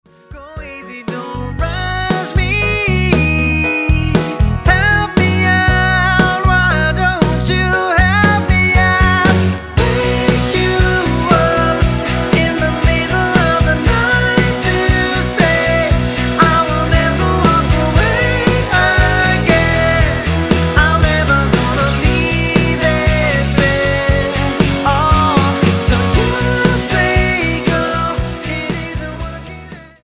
NOTE: Vocal Tracks 10 Thru 18